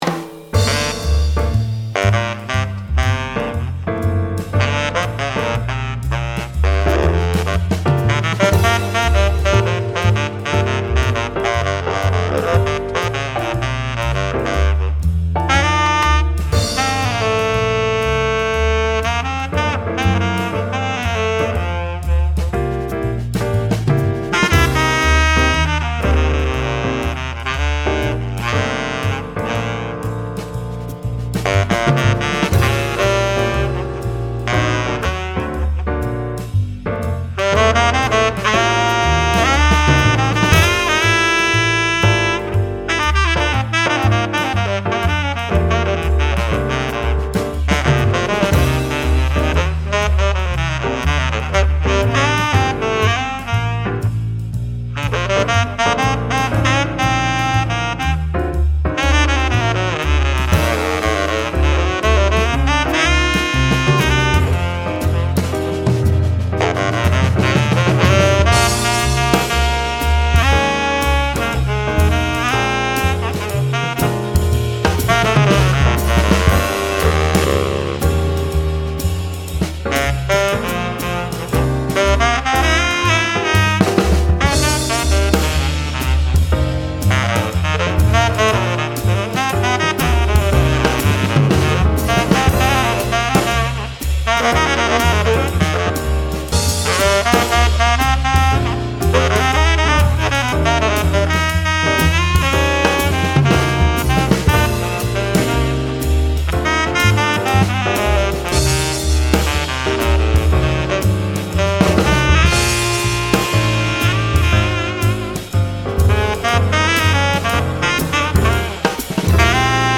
このモデルは馬蹄形のチェンバーで、音色は丸く、優しい音でありながらパワフルな音色が特徴のマウスピースです
音色の特徴ダーク、Jazz Solist よりパワフル
スタイルジャズ、ポップス
バリトン